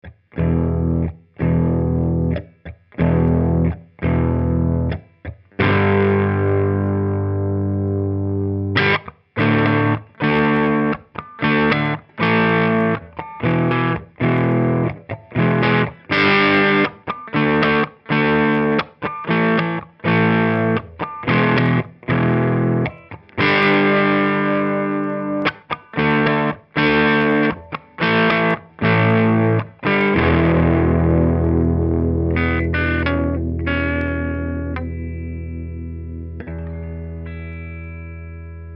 Page de samples guitare de l'ampli 12AT7 + ECL82 SE
un micro guitare/basse BD200 une carte son M-audio 1010 normalisation des samples conversion en MP3 mono
Guitare Ibanez, micro manche + central, HP Eminence legend 121 crunch
crunch_ibanez_121.mp3